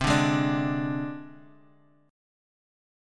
Csus2#5 chord